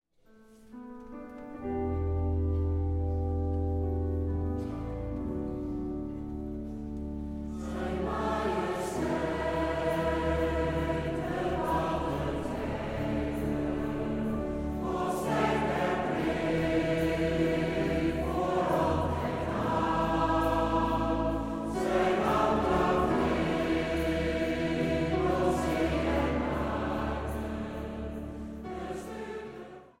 orgel
piano
viool
Strijkensemble
dwarsfluit
hobo.
Zang | Gemengd koor